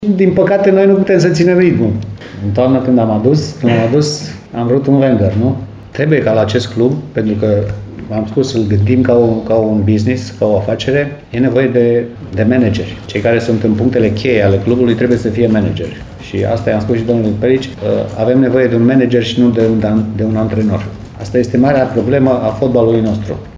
Divizionara secundă Ripensia Timișoara a susținut astăzi o conferință de presă la noul sediu al clubului, situat în Piața Libertății, în clădirea „Primăriei Vechi”, în care a realizat un bilanț al activității sportive și administrative din acest sezon și a vorbit despre perspective.